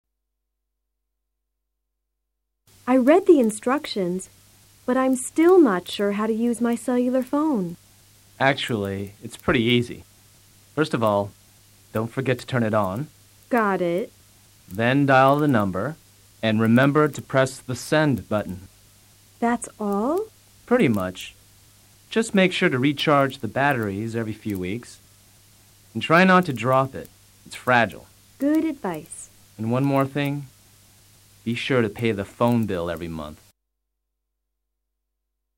Doris tiene dificultades para usar su celular y Omar le da algunas explicaciones. Escucha con atención y trata de repetir luego el diálogo.